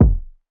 KICK OUTSIDE.wav